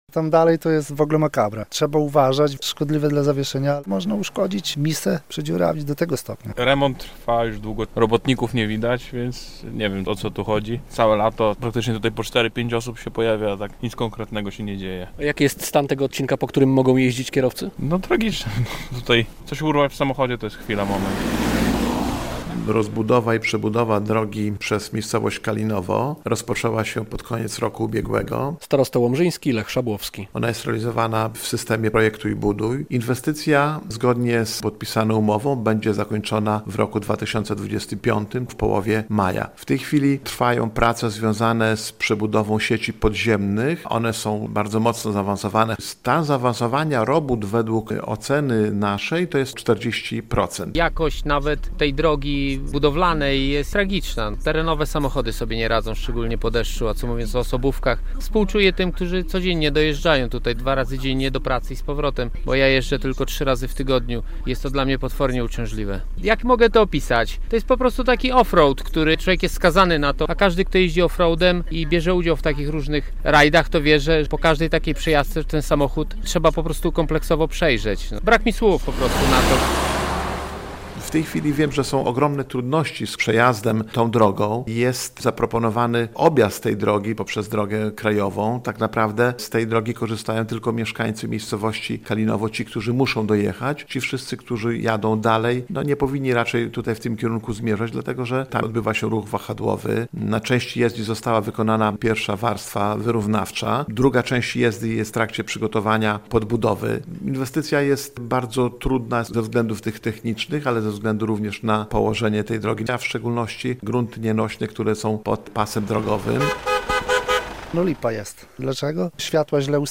relacja
- mówią kierowcy, z którymi rozmawiał nasz dziennikarz.